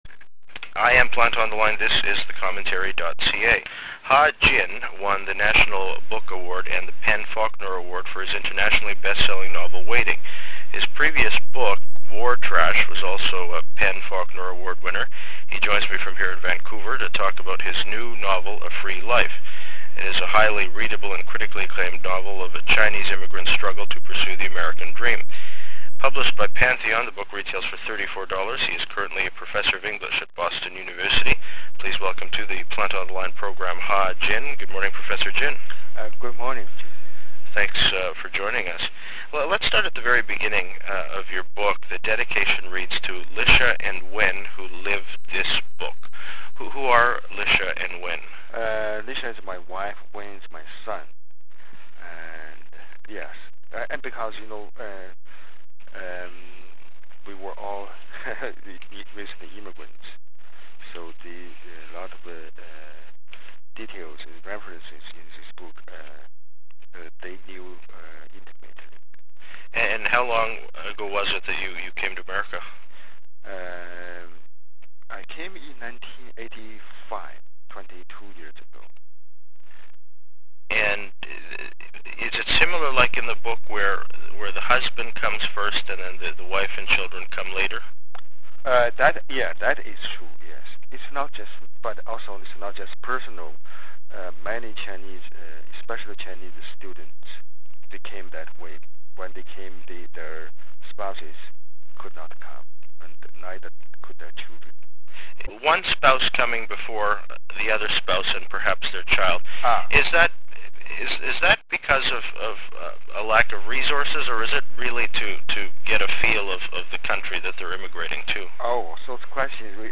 He joins me from here in Vancouver to talk about his new novel, A Free Life. It is a highly readable and critically acclaimed novel of a Chinese immigrant’s struggle to pursue the American Dream.